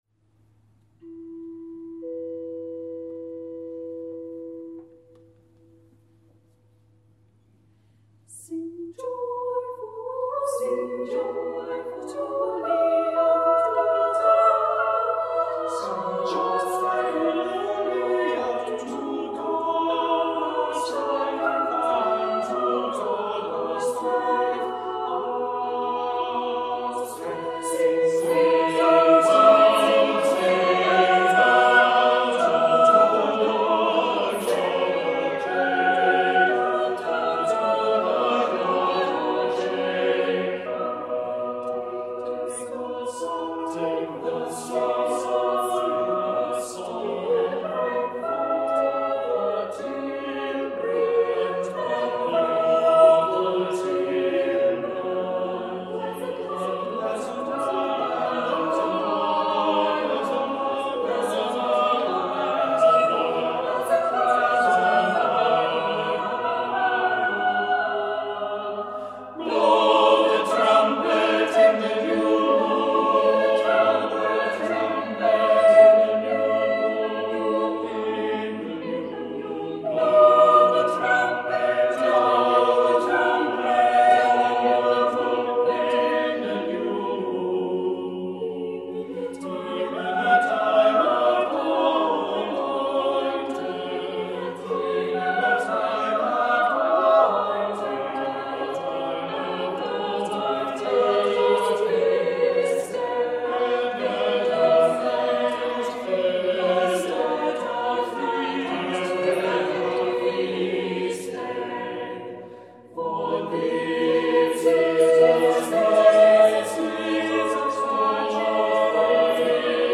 Sing Joyfully, by William Byrd, sung by the Priory Singers of Belfast at Truro Cathedral